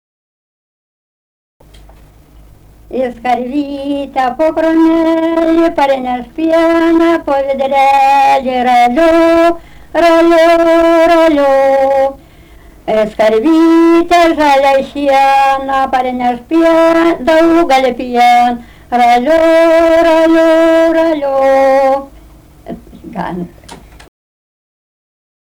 daina, vaikų
Daujėnai
vokalinis